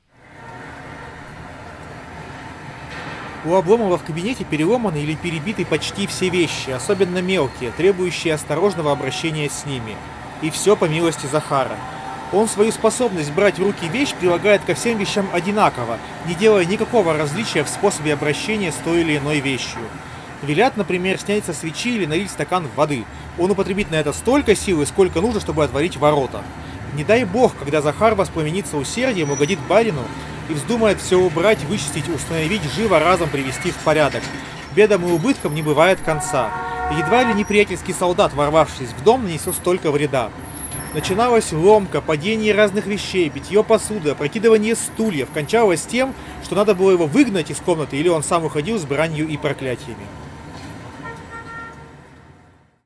4-Recorder-noise.mp3